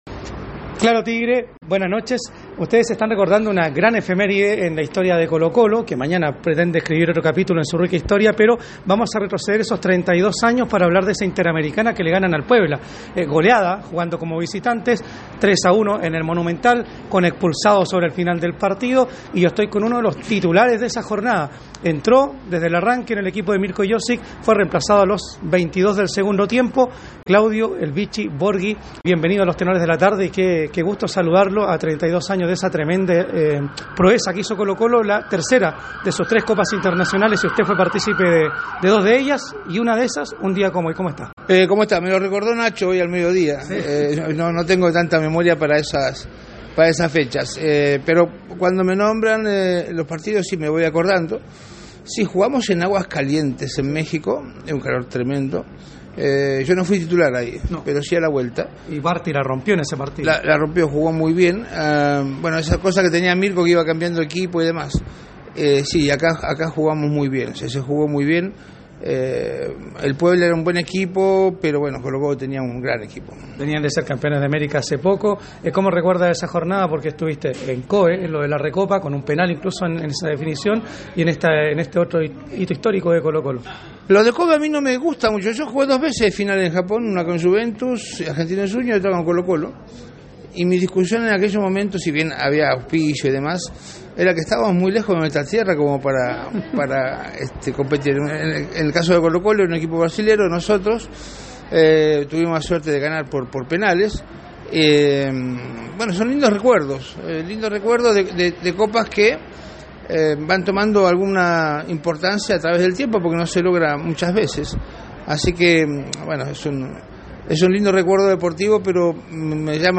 En conversación con Los Tenores de la Tarde, el “Bichi” apuntó al esquema con el que los albos deberían jugar en el Monumental de Núñez.